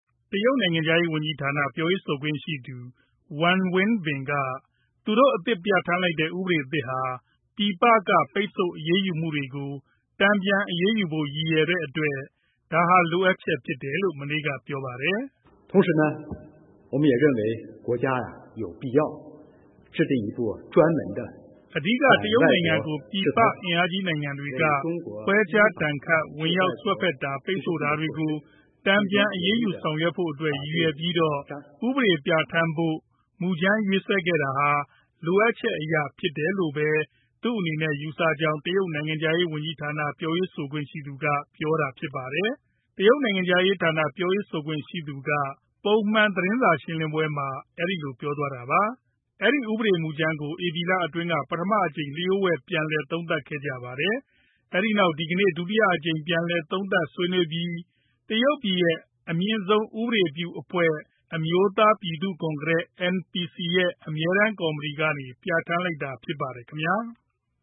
တရုတ်နိုင်ငံခြားရေးပြောရေးဆိုခွင့်ရှိသူက ပုံမှန်သတင်းစာရှင်းလင်းပွဲမှာ ပြောသွားတာဖြစ်ပါတယ်။